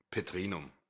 The Bischöfliches Gymnasium Petrinum (German pronunciation: [ˌpeˈtʀiːnʊm]
De-at_Petrinum.ogg.mp3